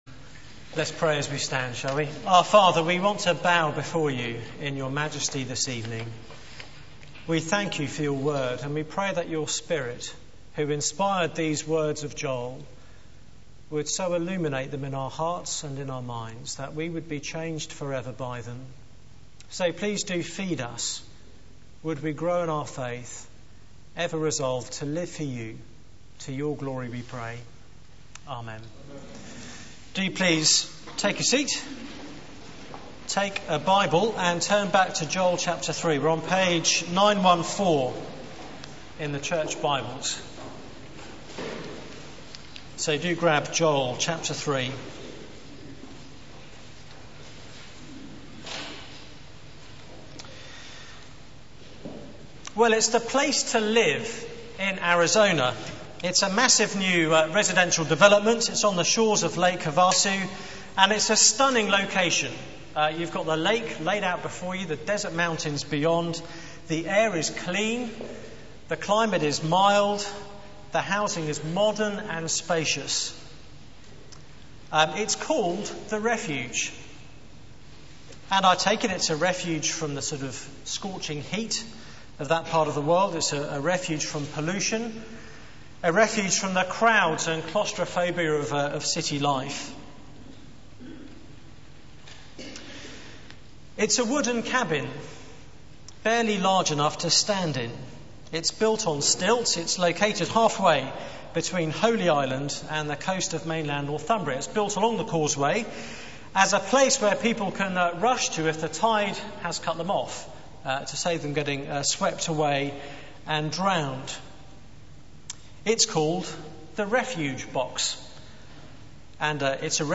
Media for 9:15am Service on Sun 19th Oct 2008 18:30 Speaker: Passage: Joel 3 Series: A day is coming Theme: Come to the valley of decision Sermon slides Open Search the media library There are recordings here going back several years.